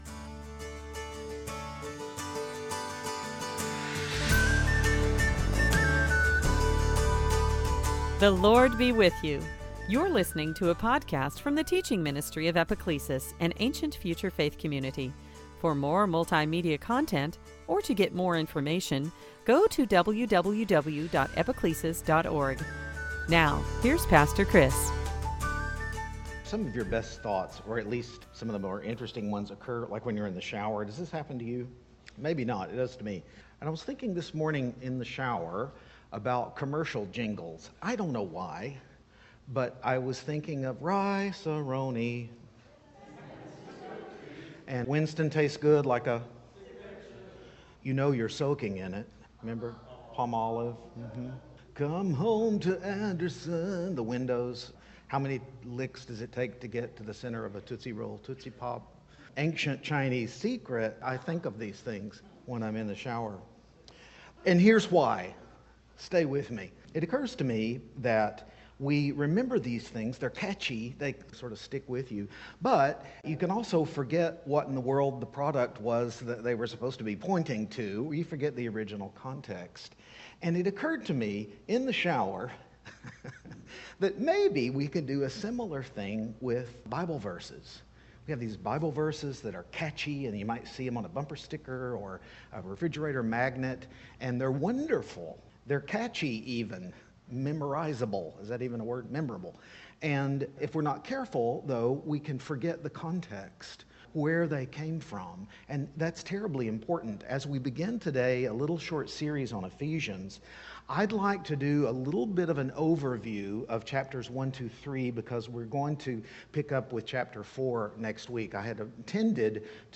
In this first in a sermon series on Ephesians, we looked at several well-known Bible verses and their all-important context.